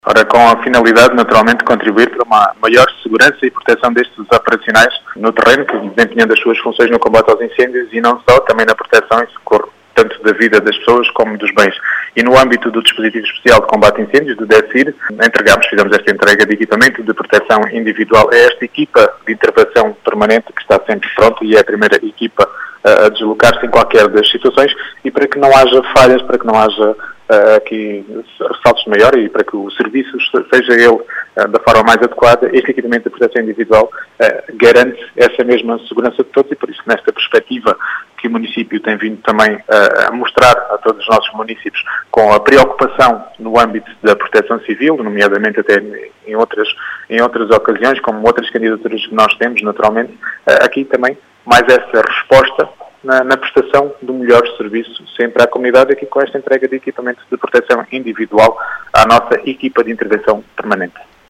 As explicações de Rui Raposo, presidente da Câmara de Vidigueira.